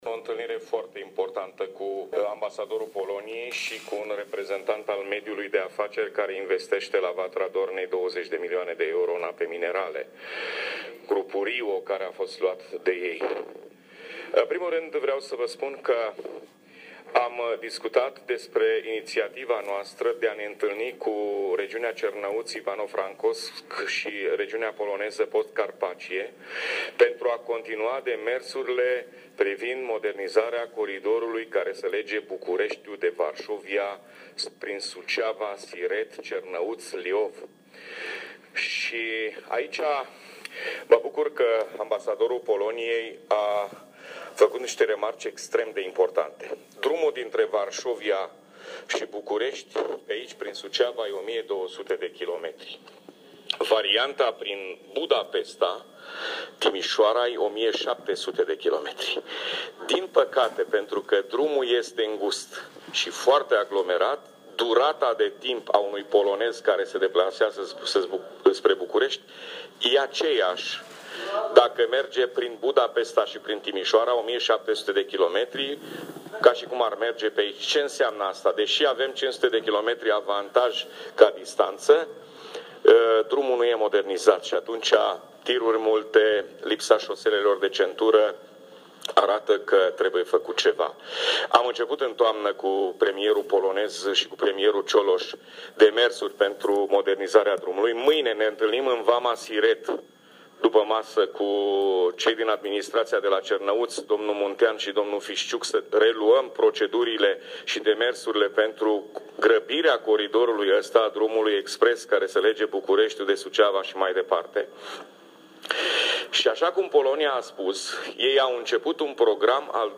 Declaraţia completă a preşedintelui Consiliului Judeţean Suceava, Gheorghe Flutur, în urma întâlnirii cu ambasadorul Republicii Polone, o puteţi asculta mai jos: